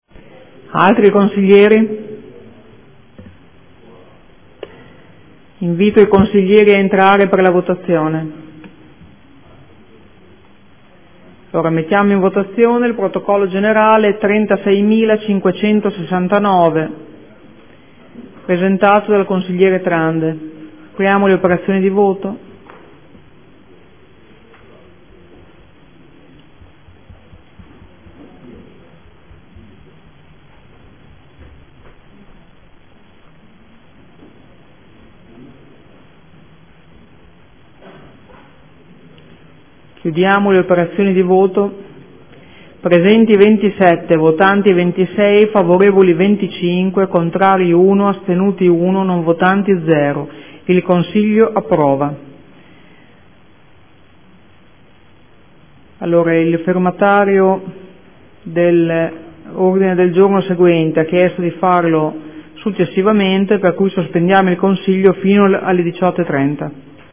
Seduta del 09/04/2015.
Presidentessa